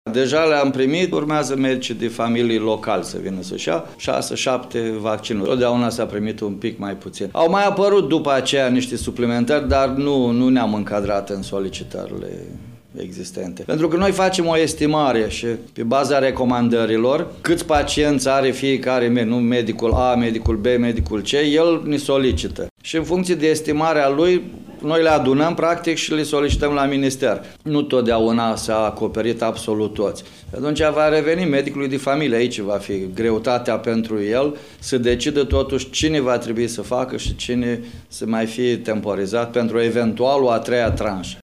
Săptămâna viitoare, la Suceava ar mai trebui să ajungă aproximativ 5600 de vaccinuri, a declarat Ludovic Abiţei, director DSP Suceava.